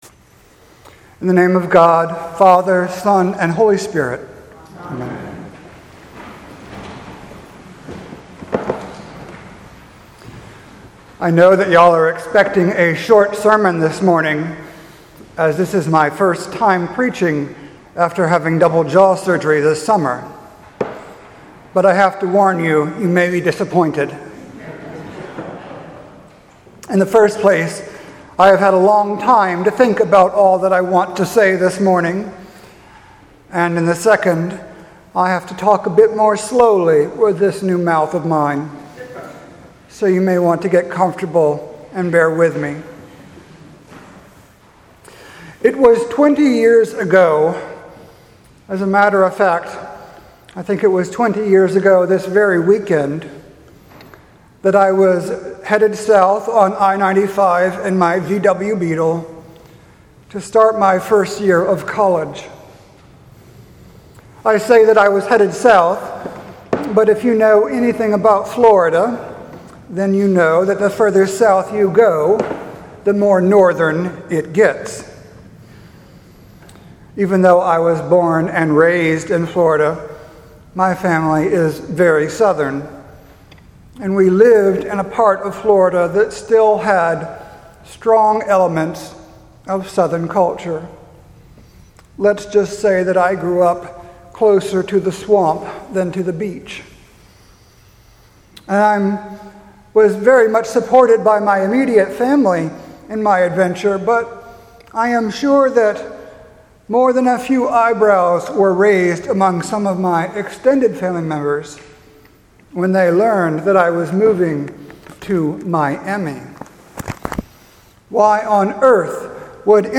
Sermon for August 27th, 2017, the twelfth Sunday after Pentecost.